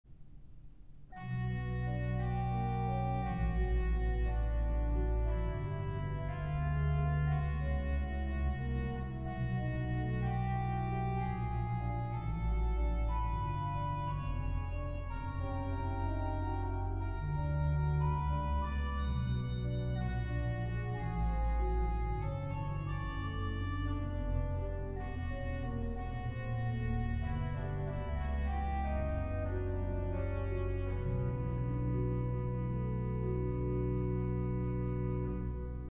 Enregistré en 1996 sur le grand orgue.